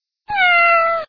meow.wav